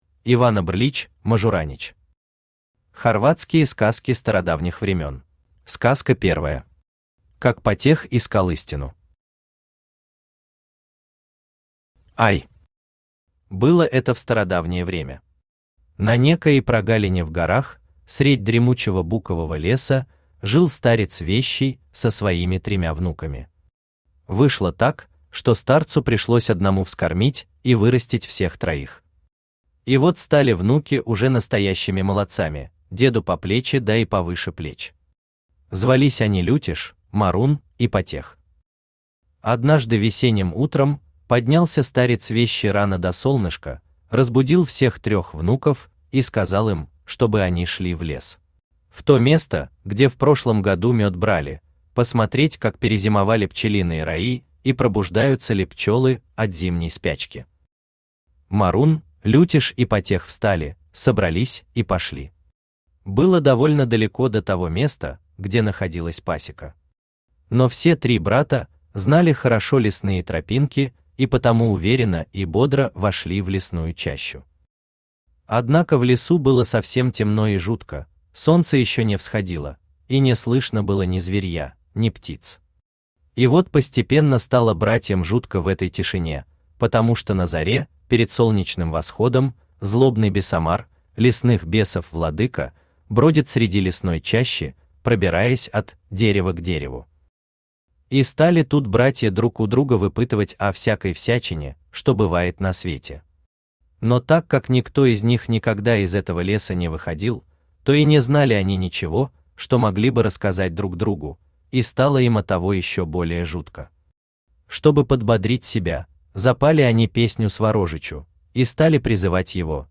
Aудиокнига Хорватские сказки стародавних времён Автор Ивана Брлич-Мажуранич Читает аудиокнигу Авточтец ЛитРес.